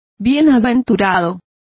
Complete with pronunciation of the translation of blessed.